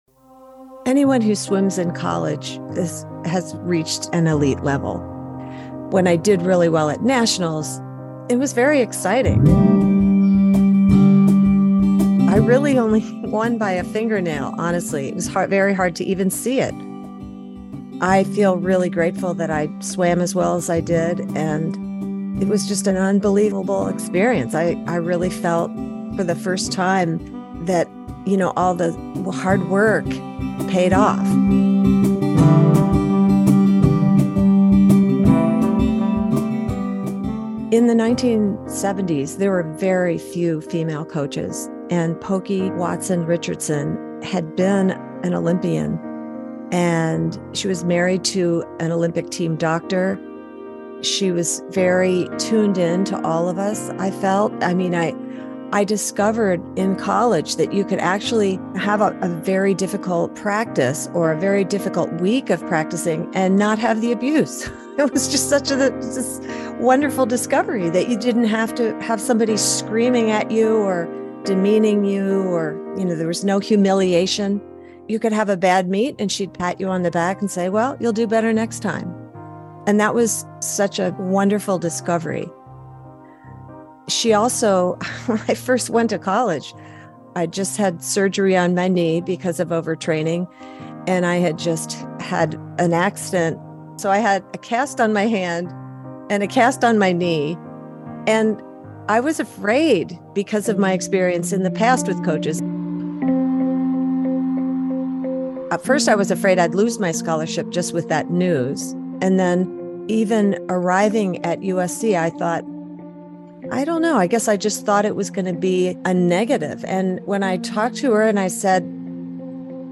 Interviews and audio